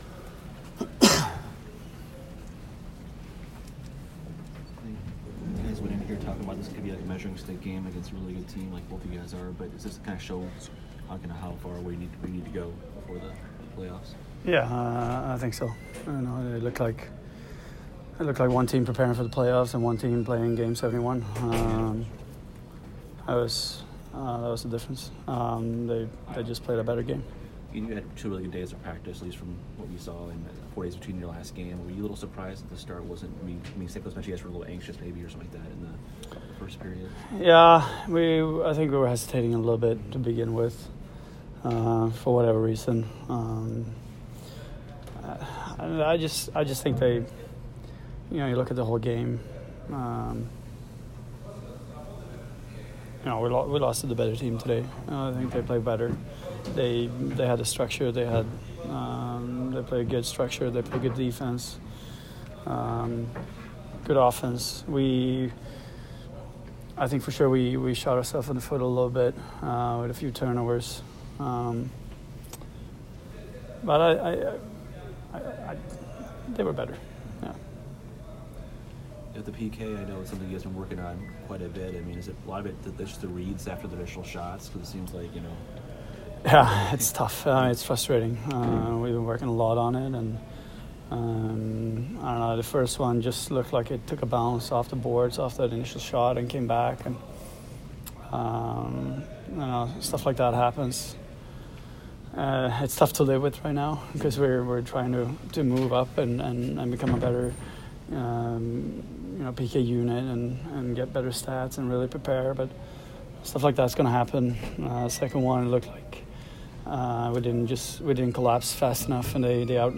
Anton Stralman post-game 3/17